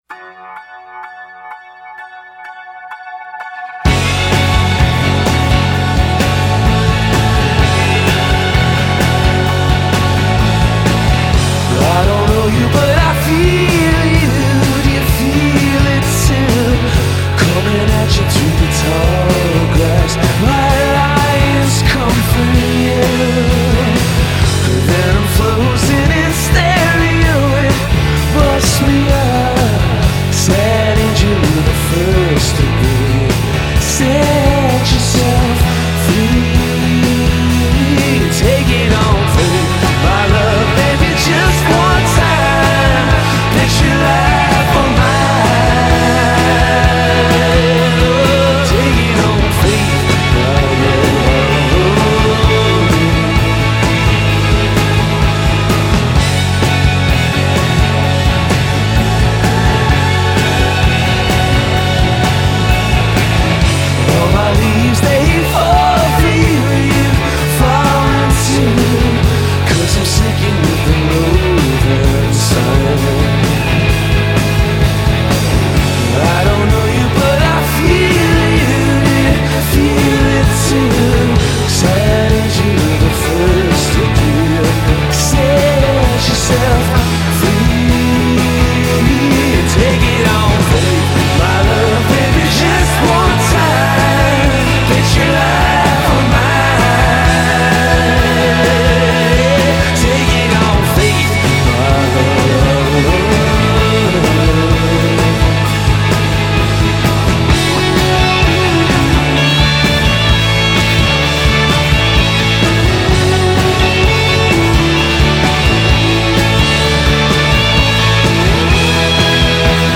Very groovy…. classic rock-ish sounding.